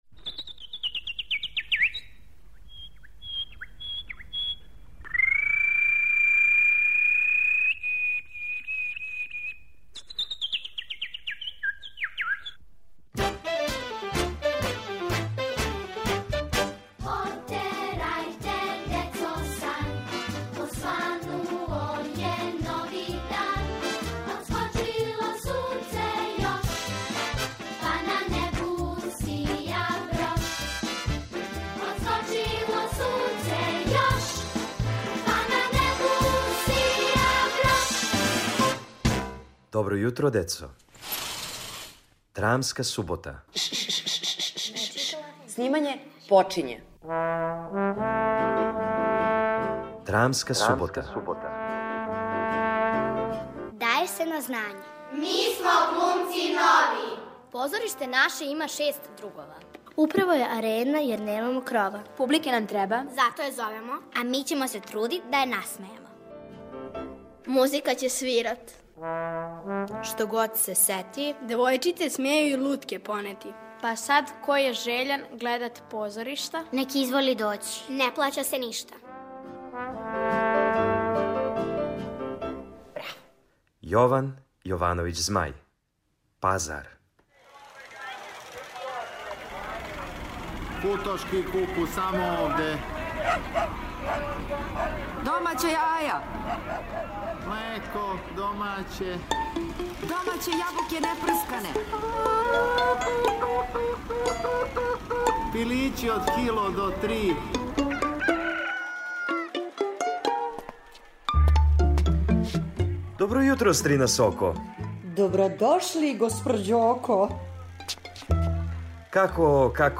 У "Драмској суботи" слушате мале драмске форме Јована Јовановића Змаја. Данашња се дешава на пијаци...